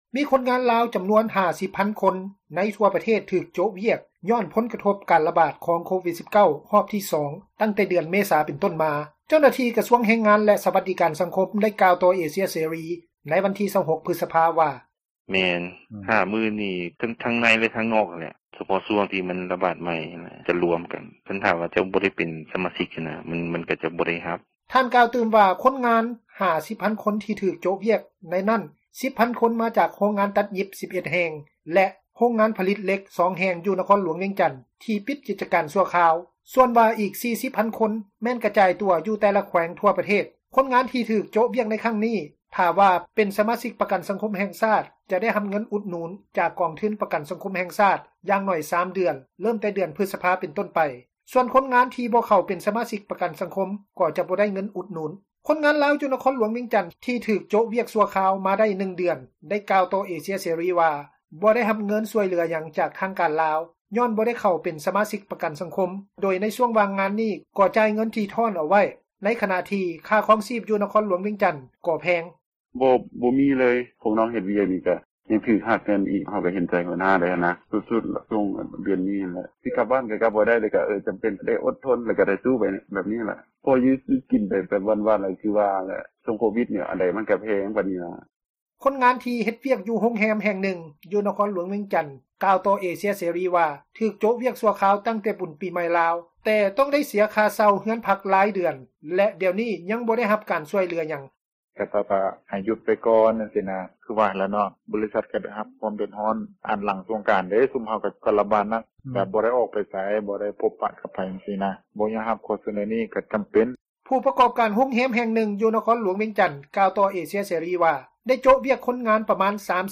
ເຈົ້າໜ້າທີ່ ກະຊວງແຮງງານ ແລະ ສວັດດີການສັງຄົມ ໄດ້ກ່າວຕໍ່ເອເຊັຽເສຣີ ໃນວັນທີ 26 ພຶສພາ ວ່າ: